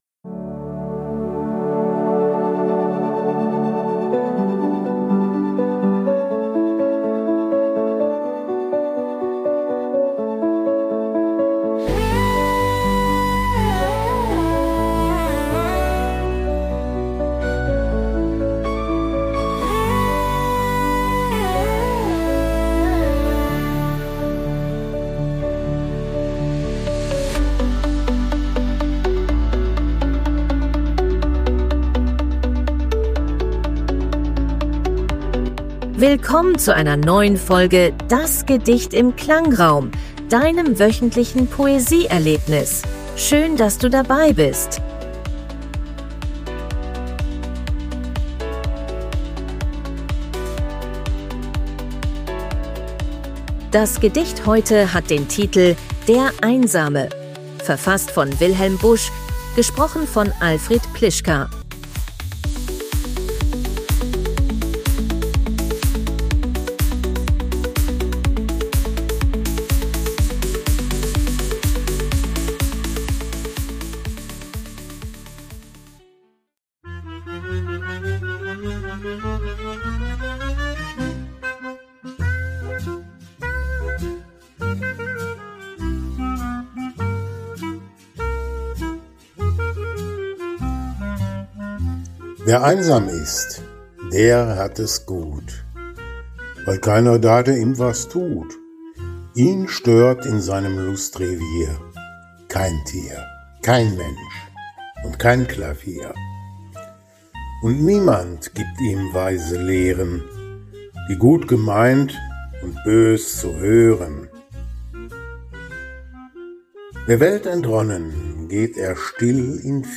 / untermalt von KI-generierter Musik.